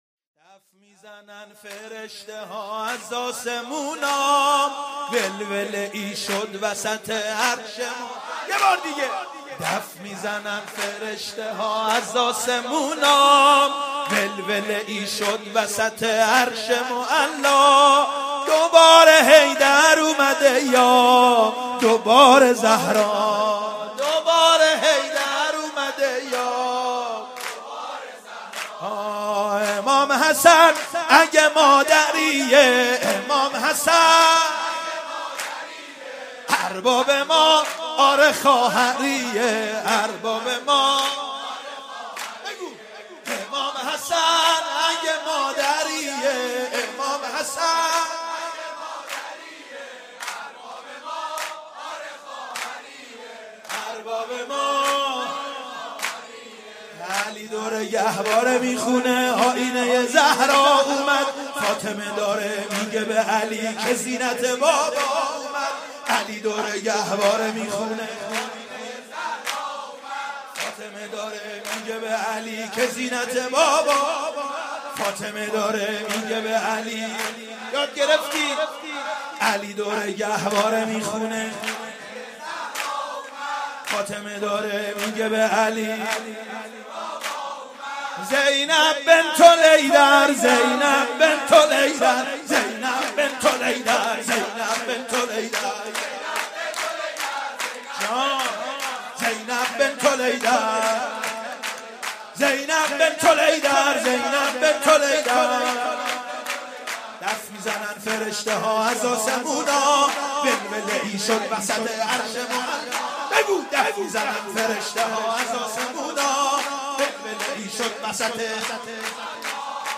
مولودی دف می زنند فرشته ها به مناسبت میلاد حضرت زینب (س)